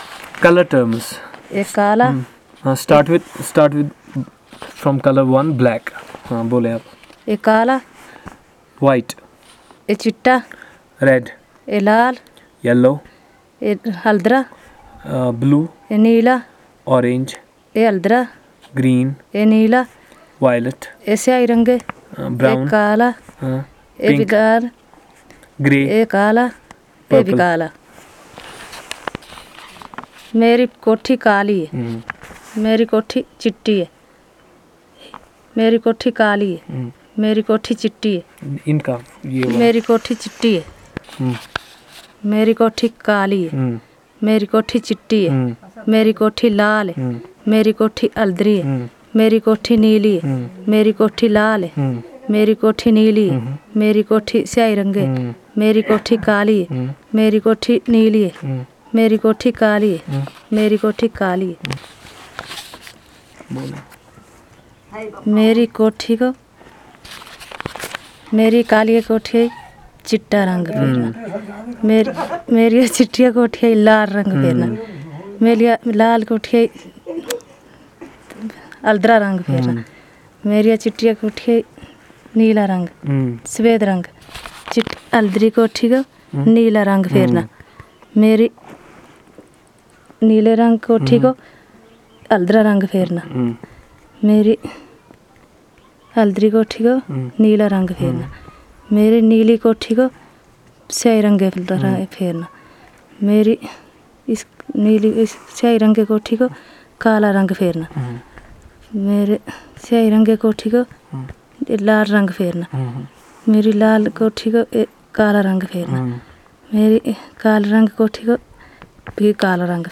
Elicitation of words and sentences about colour terms